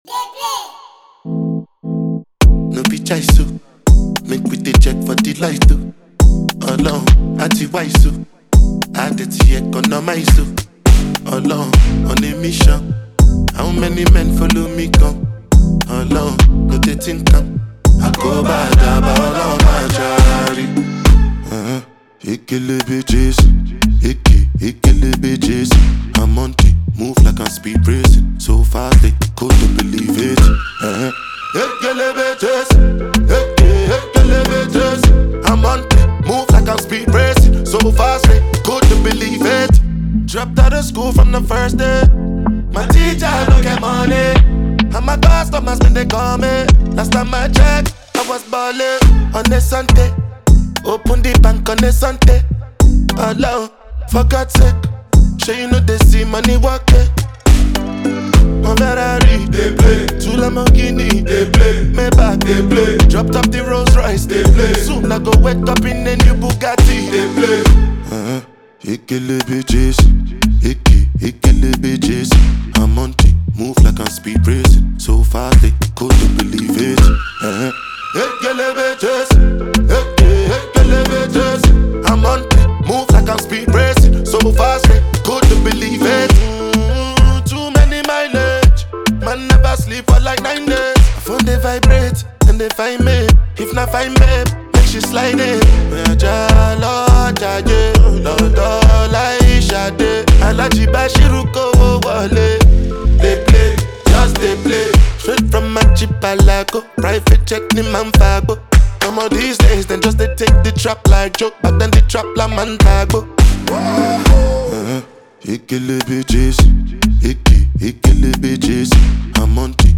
Genre: African.